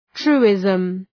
Προφορά
{‘tru:ızm}